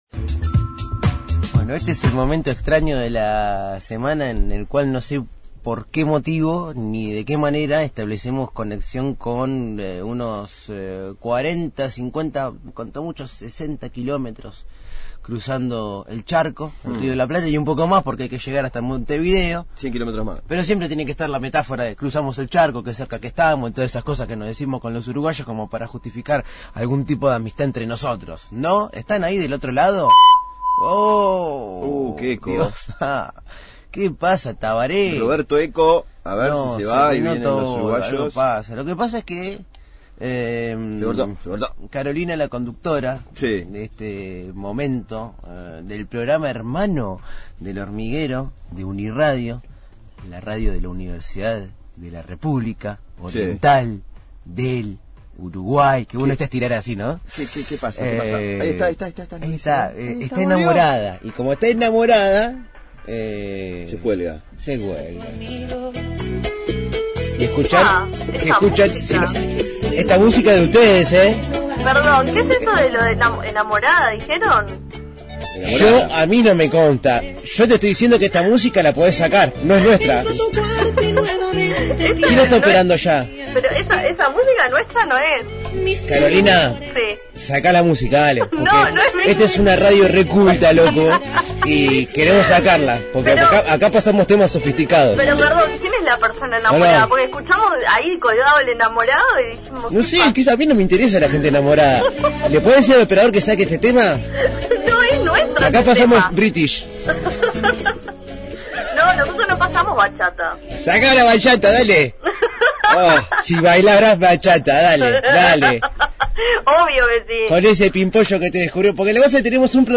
Dúplex con UNI Radio Montevideo – Radio Universidad